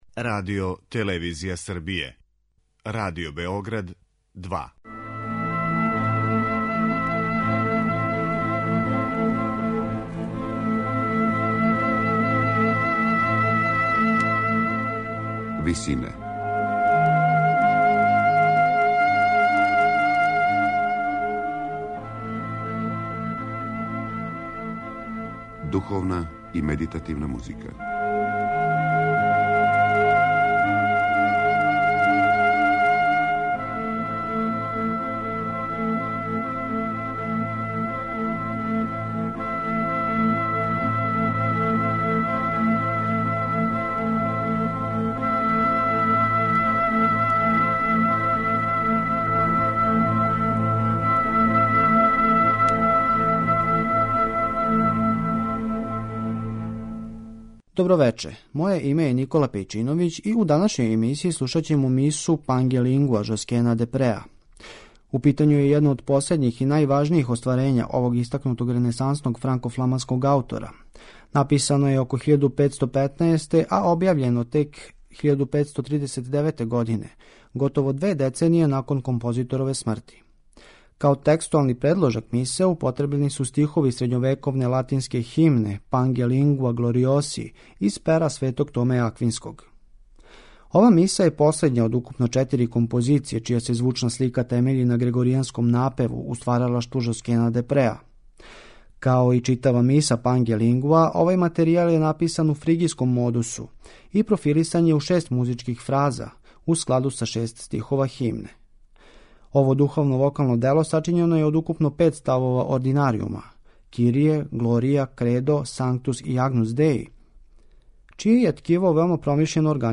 Вечерашње Висине посвећене су Миси „Панге лингва" ренесансног композитора Жоскена де Преа.
медитативне и духовне композиције